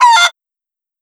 EEnE Chicken.wav